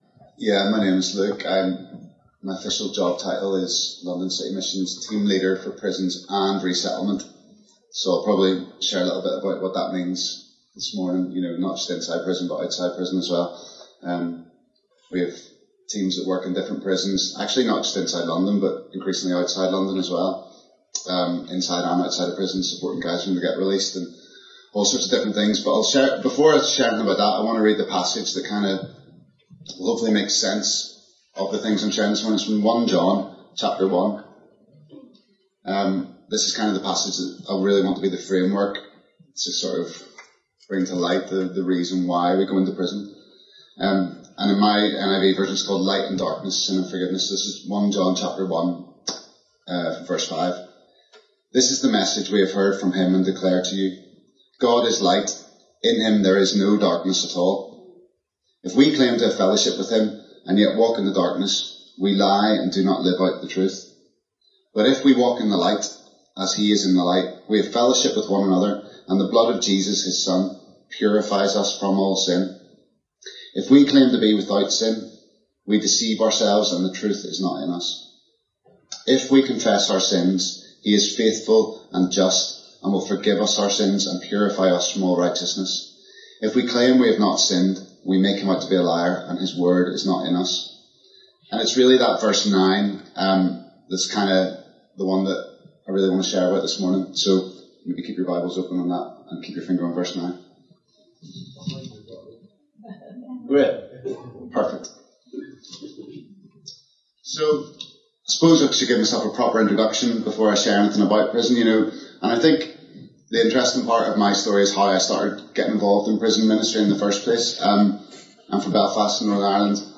Download Sent Into Prison | Sermons at Trinity Church